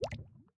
drip_lava4.ogg